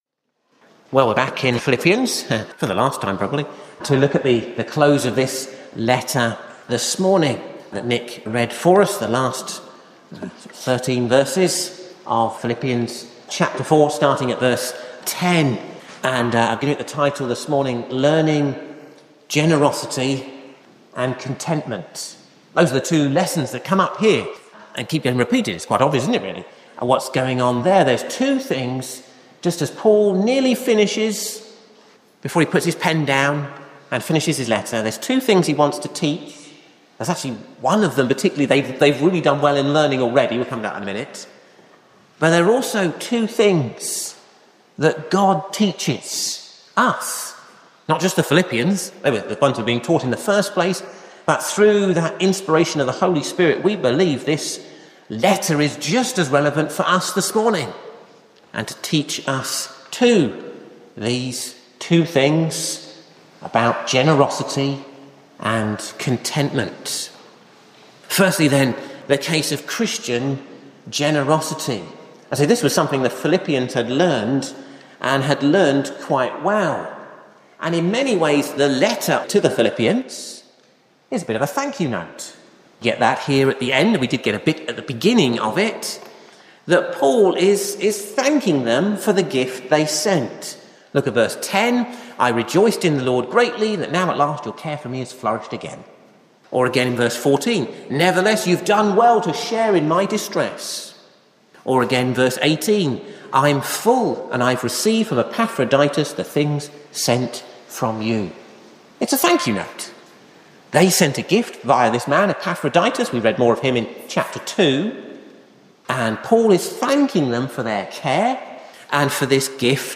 'Philippians: Shining Like Stars' Sermon Series: Ashbourne Baptist Church 2026